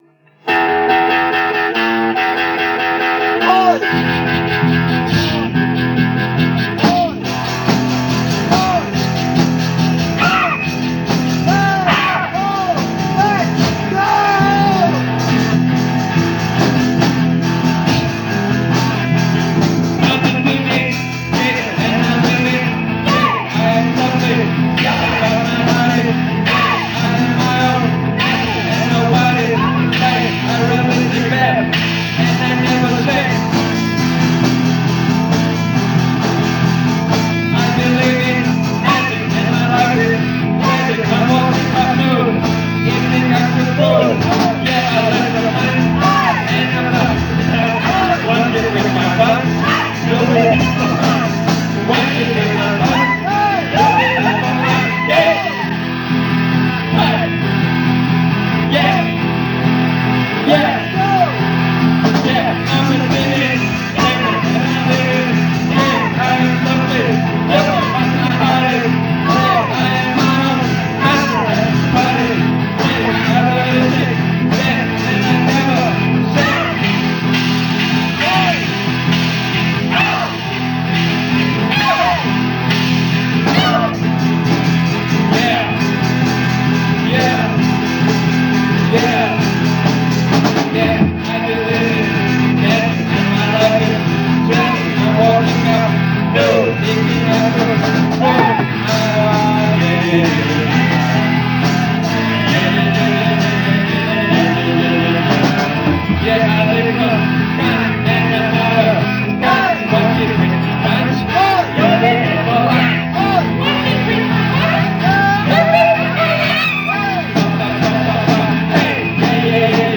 ALEATORIČKO INTUITIVNO-EKSPERIMENTALNi